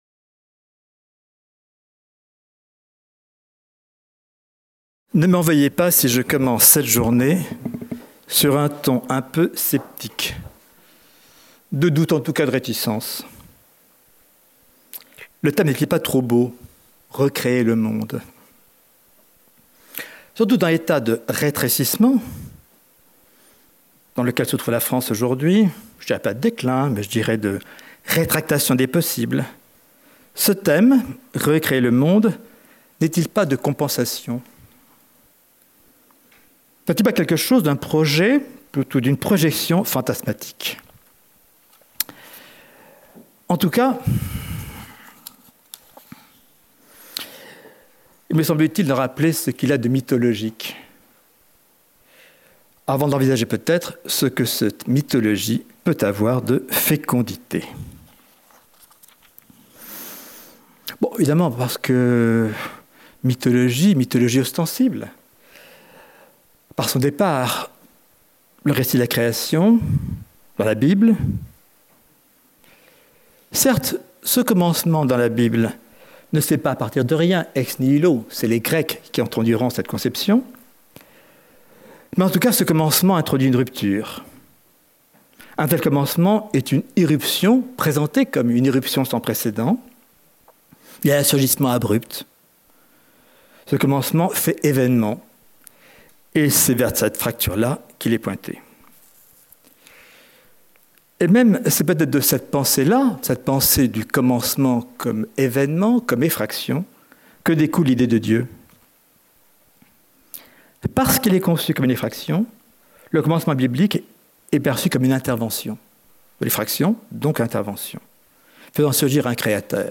Jeudi 19 mai - Philharmonie, salle de conférence 9h30 Création : origines, sens et mythologie : mythologie de la création et sa fécondité par François JULLIEN